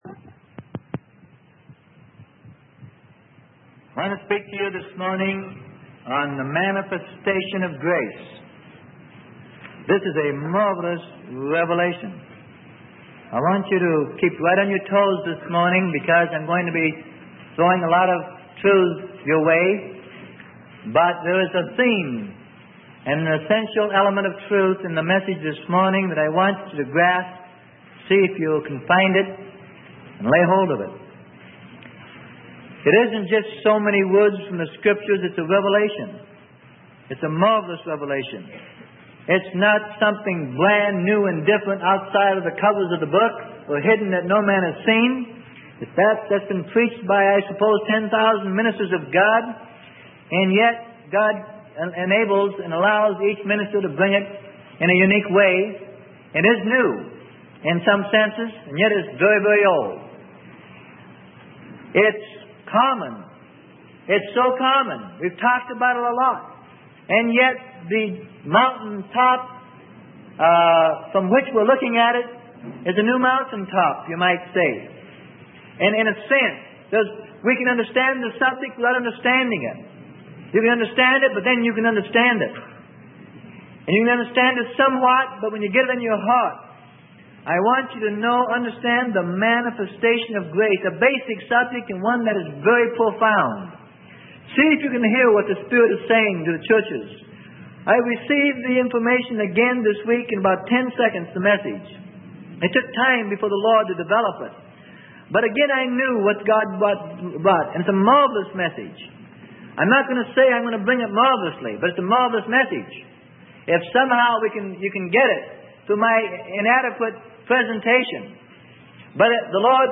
Sermon: Manifestation of Grace - Titus 2:11-12 - Freely Given Online Library